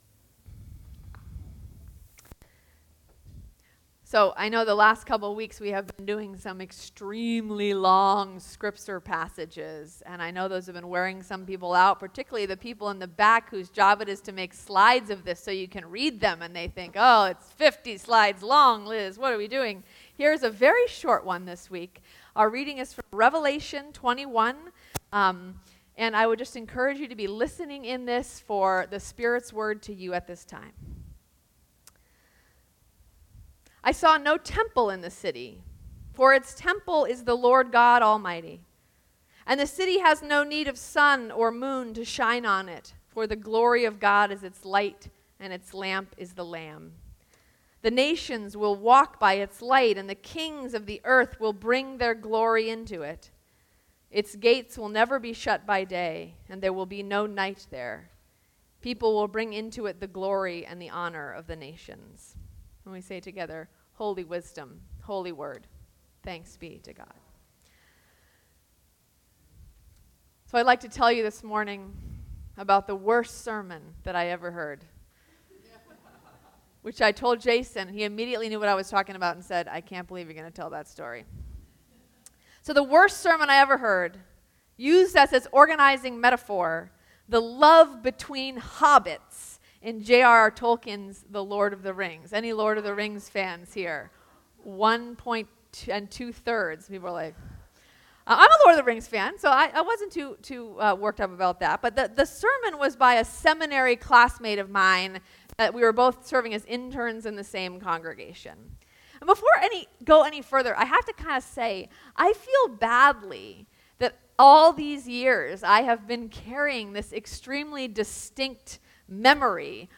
2021-08-01 Sermon: The Reflection